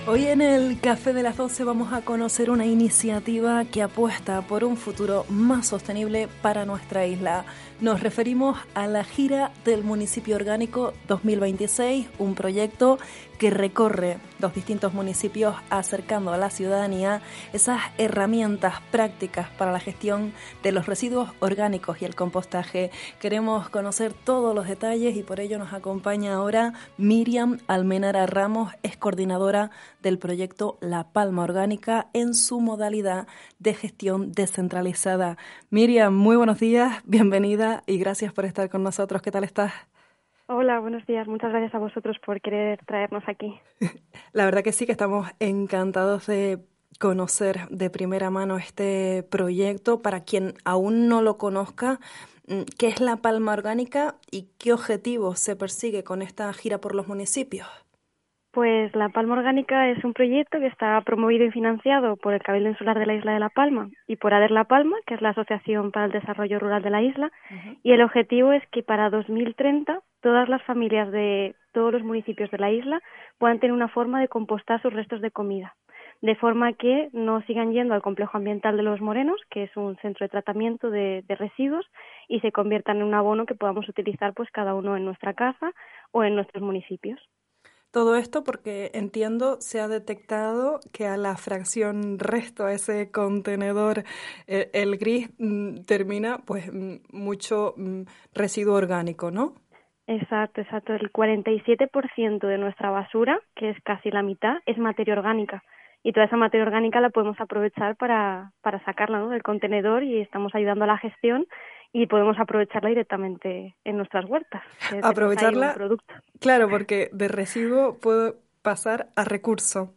durante una entrevista en el programa El